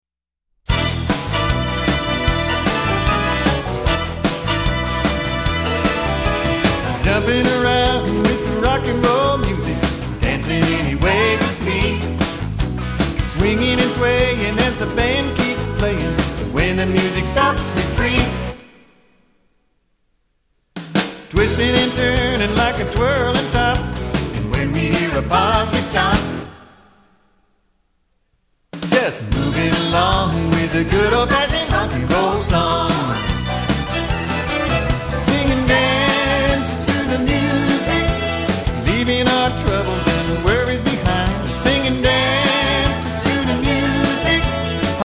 Listen to a sample of this song